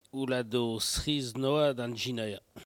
Patois